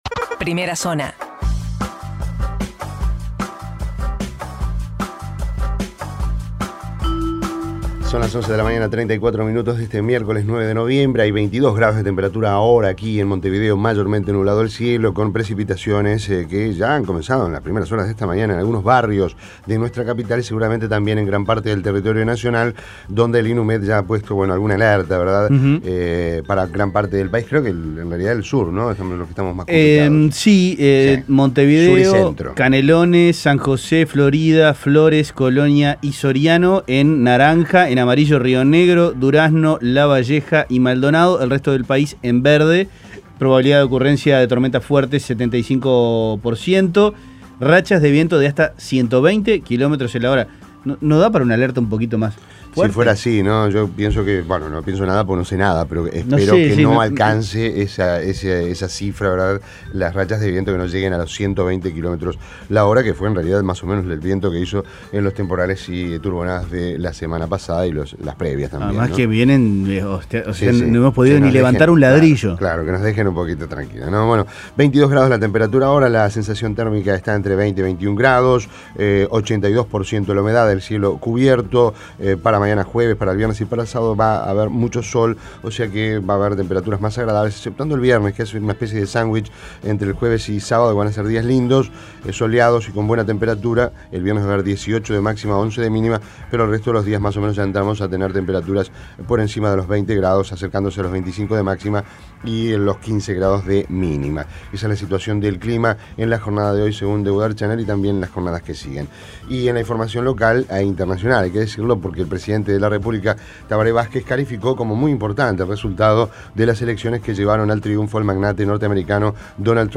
Las principales noticias del día, resumidas en la Primera Zona de Rompkbzas.